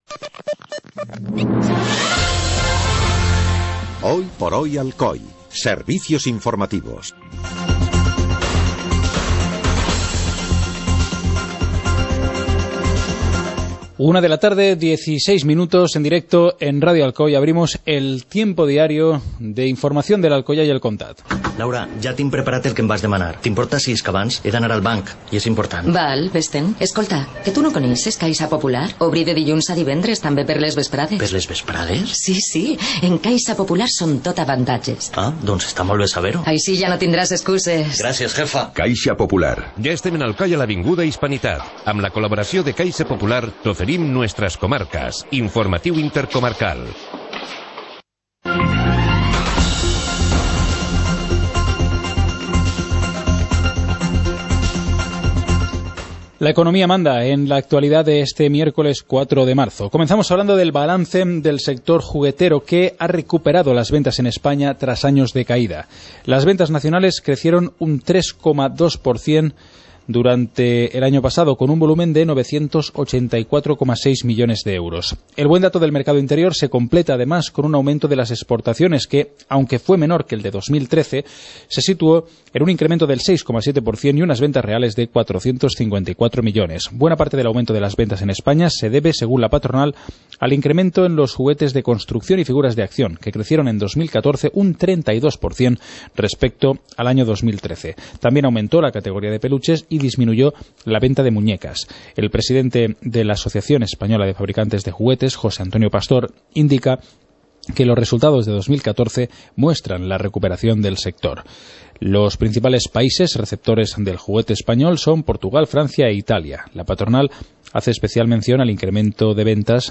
Informativo comarcal - miércoles, 04 de marzo de 2015